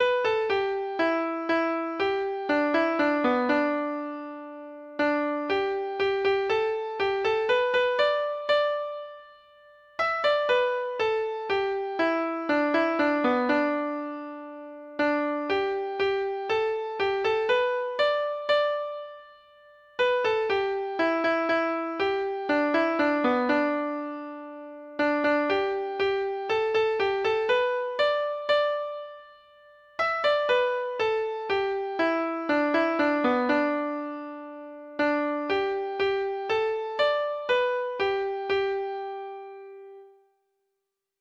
Folk Songs